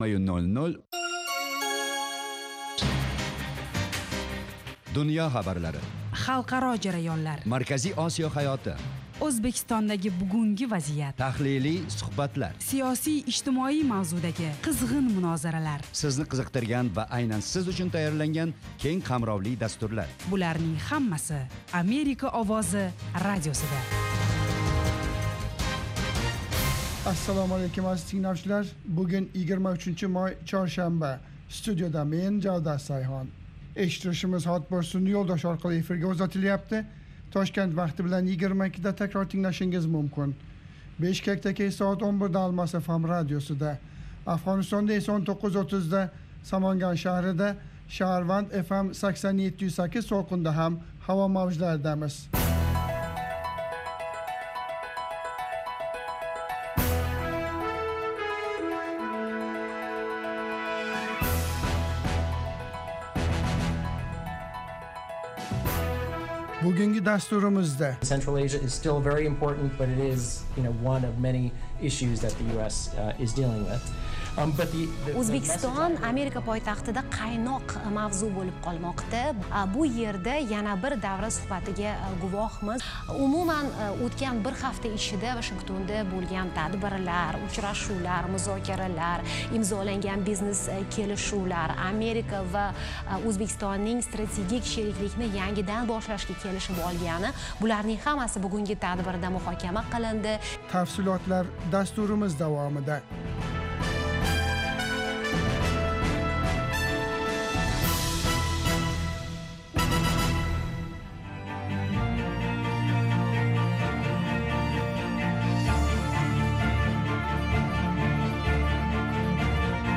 Toshkent vaqti bilan har kuni 20:00 da efirga uzatiladigan 30 daqiqali radio dastur kunning dolzarb mavzularini yoritadi. Xalqaro hayot, O'zbekiston va butun Markaziy Osiyodagi muhim o'zgarishlarni, shuningdek, AQSh bilan aloqalarni tahlil qiladi.